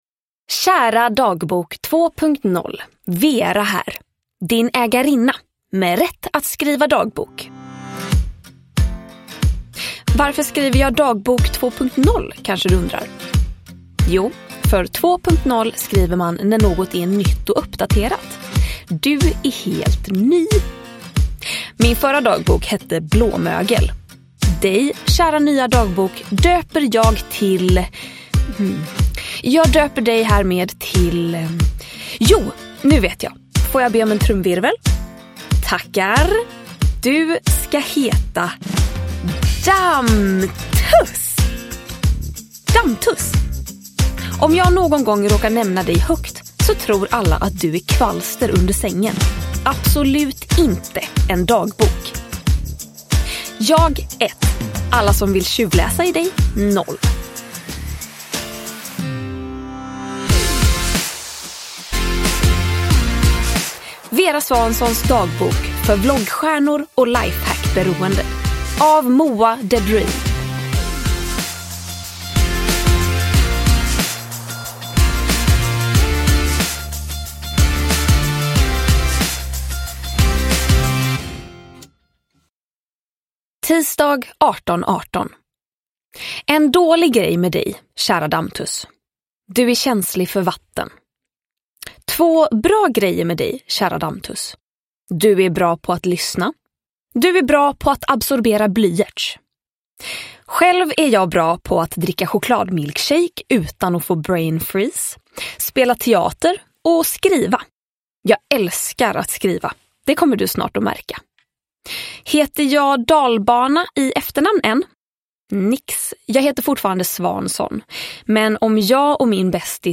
Vera Svansons dagbok för vloggstjärnor och lifehackberoende – Ljudbok – Laddas ner
Uppläsare: Clara Henry